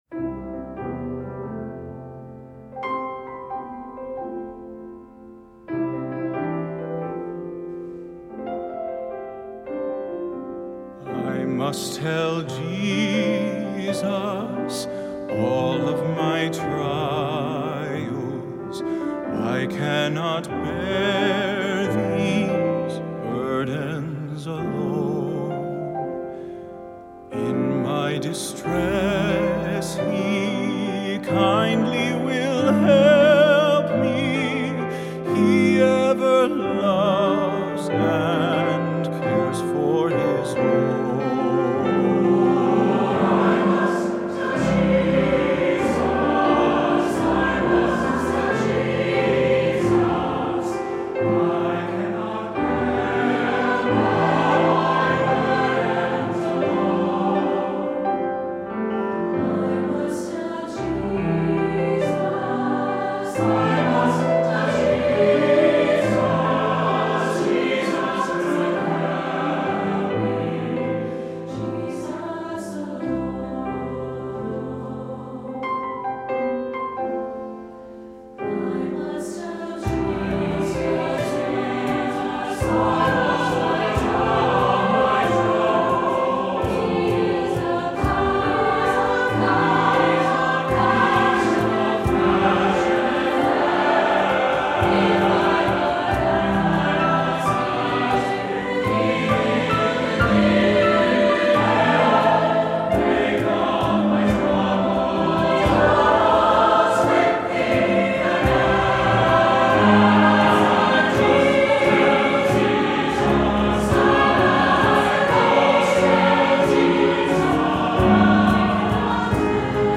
Voicing: SATB, Piano and Optional Hand Drum